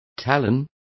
Complete with pronunciation of the translation of talons.